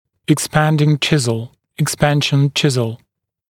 [ɪk’spændɪŋ ‘ʧɪzl], [ɪk’spænʃn ‘ʧɪzl] [ek-]